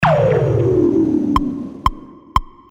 красивые
Electronic
спокойные
без слов
electro